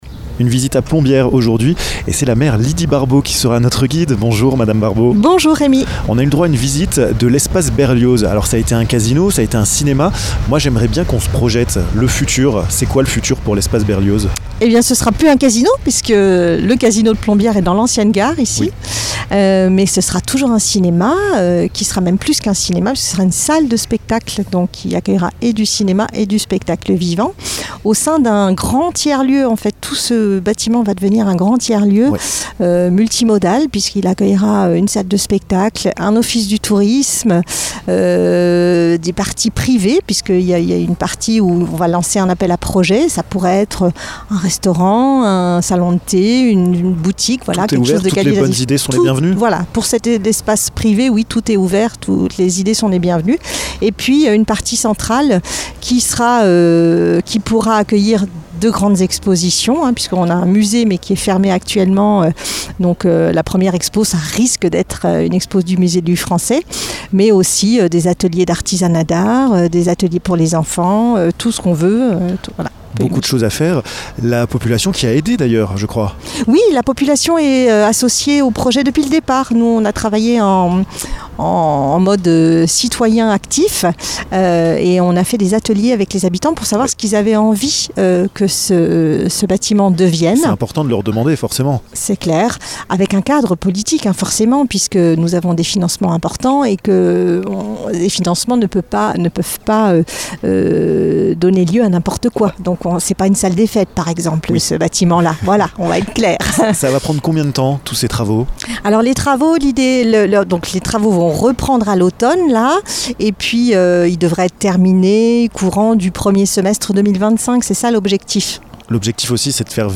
%%La rédaction de Vosges FM vous propose l'ensemble de ces reportages dans les Vosges%%
Lydie Barbaux, maire de Plombières, vous explique les transformations que va subir l'espace Berlioz. Du changement, des nouveautés, la population a même donné un coup de pouce!